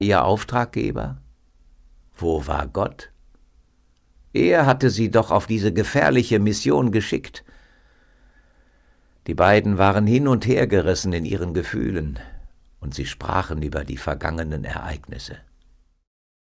Ein Musical für die ganze Familie
Mit fetzigen Liedern und modernen Arrangements.
Kinderlieder